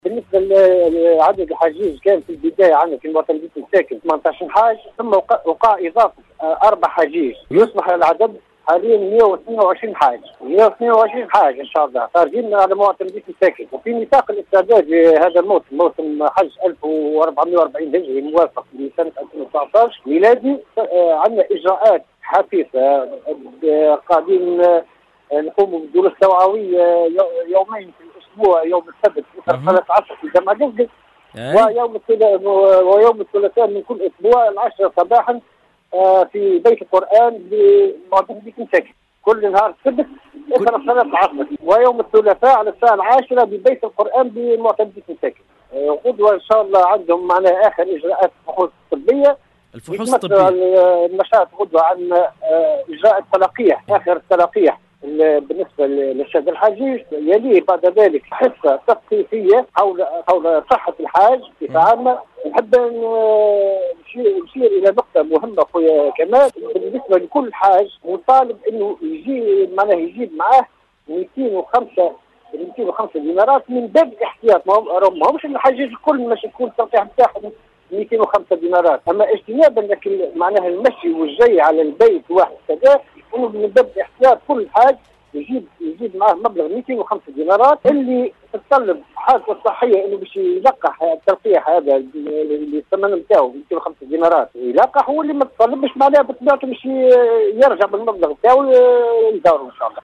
غدا السبت : 122 حاج عن معتمديّة مساكن يقومون بالتلقيح (تصريح)